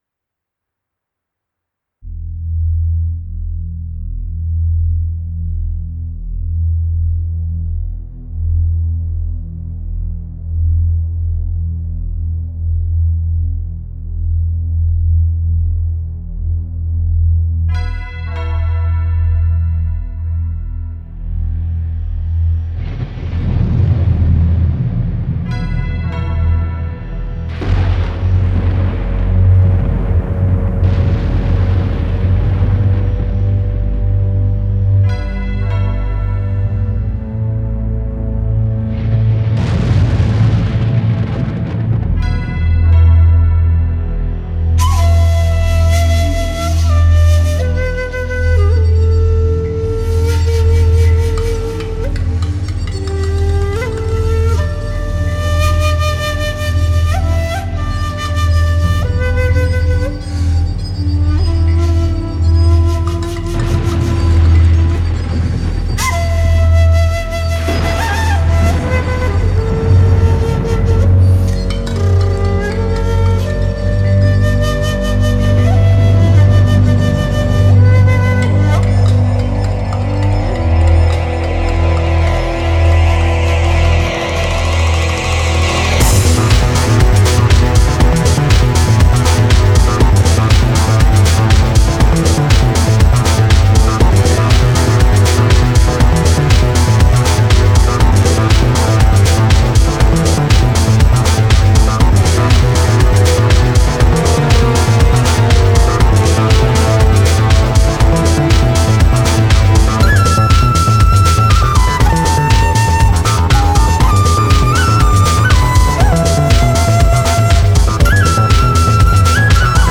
纯音雅乐